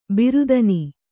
♪ birudani